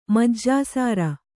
♪ majjāsāra